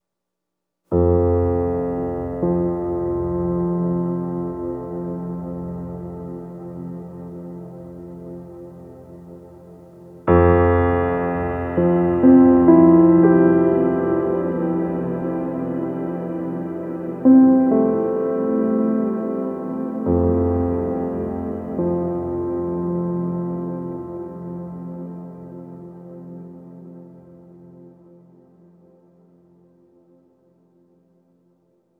Reverb Piano 07.wav